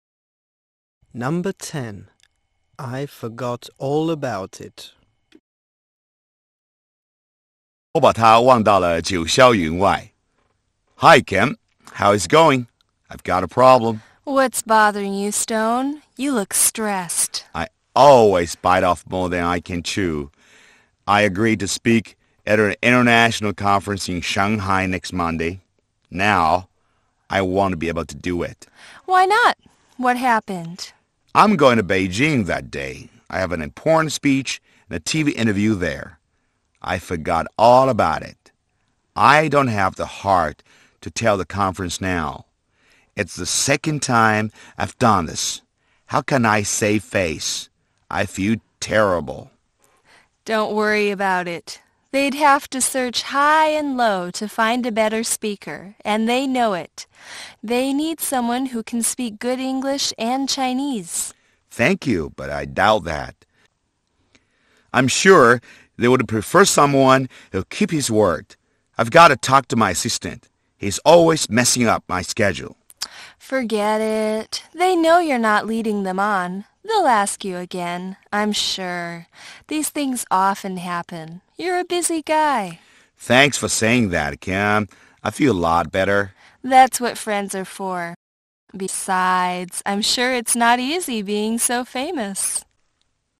One friend is telling the other about his busy schedule.